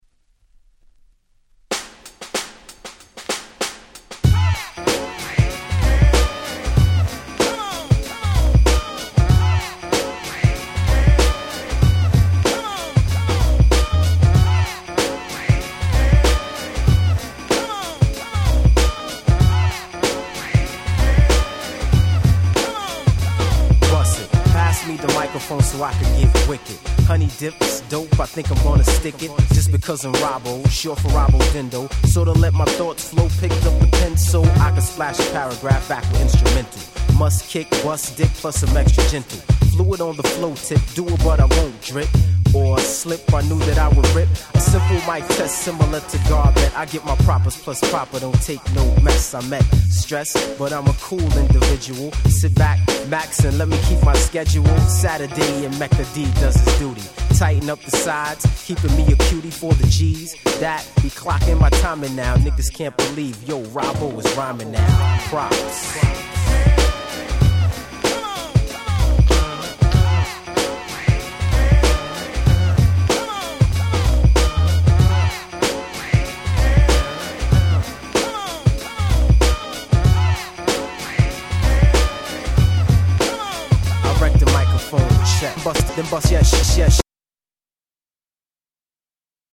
ピートロック Boom Bap 90’s ブーンバップ